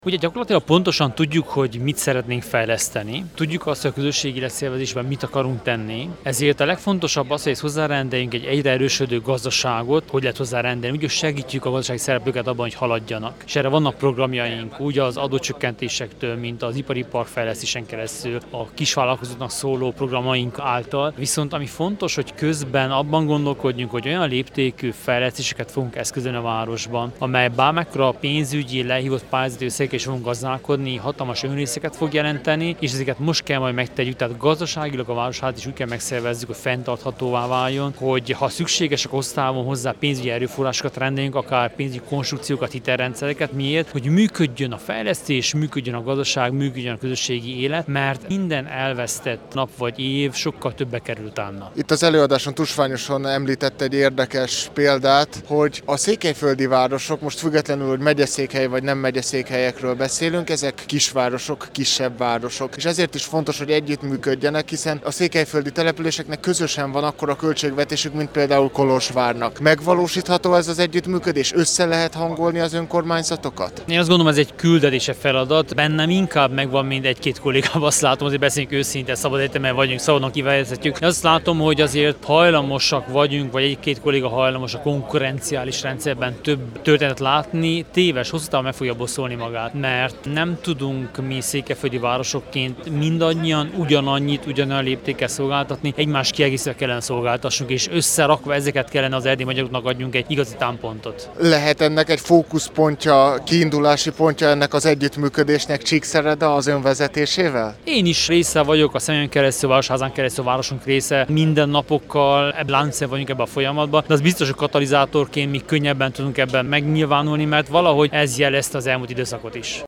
Kerekasztalbeszélgetés a székelyföldi városok polgármestereivel
A 33. Tusványoson tartott önkormányzati kerekasztal-beszélgetésen részt vett, Soós Zoltán, Marosvásárhely polgármestere, Korodi Attila, Csíkszereda polgármestere, Antal Árpád, Sepsiszentgyörgy polgármestere és Szakács-Paál István, Székelyudvarhely frissen megválasztott polgármestere.